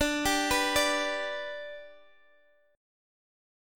Listen to Dsus6 strummed